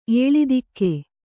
♪ ēḷidikke